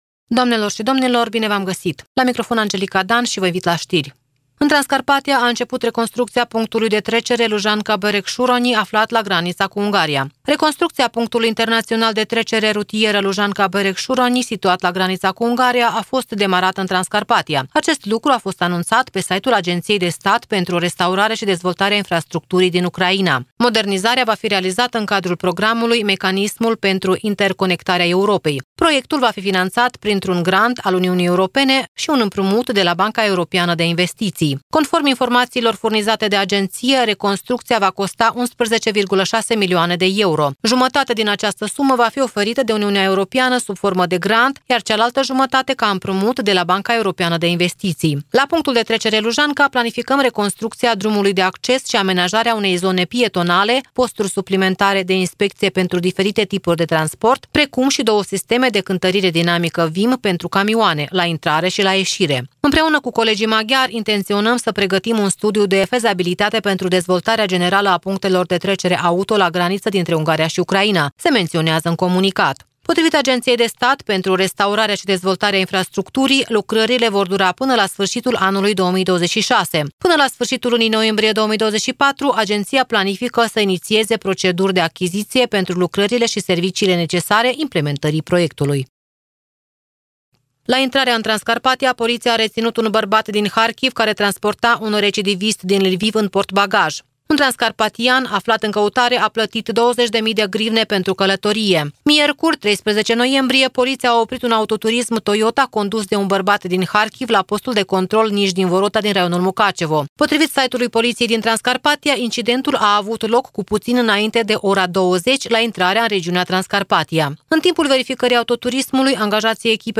Știri de la Radio Ujgorod.